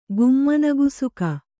♪ gummana gusuka